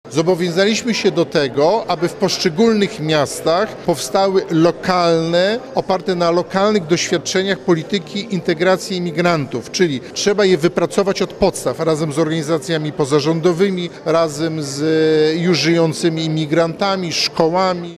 Dziś w Gdańsku przedstawiciele miast podpisali porozumienie. – Idziemy pod prąd w porównaniu do polityki rządu, ale uważamy że tak trzeba – mówi prezydent Gdańska Paweł Adamowicz.